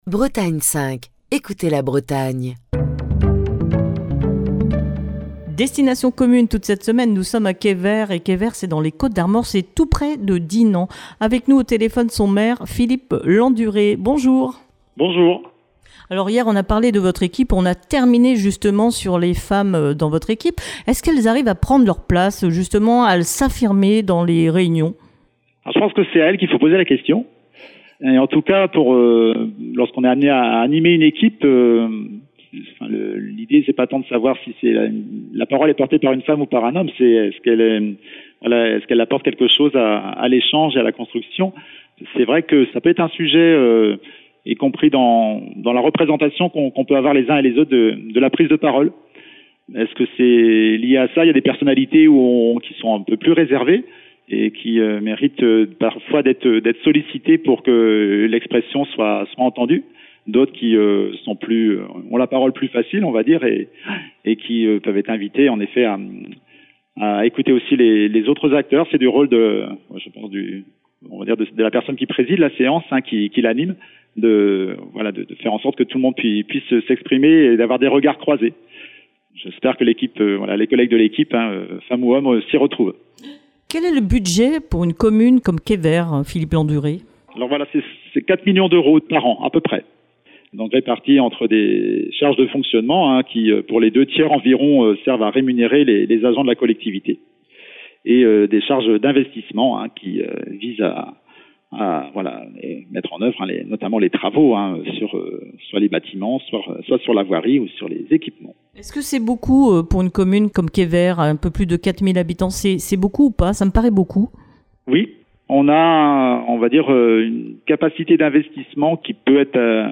Destination commune fait escale cette semaine à Quévert dans les Côtes-d'Armor. Philippe Landuré, le maire de Quévert, présente sa commune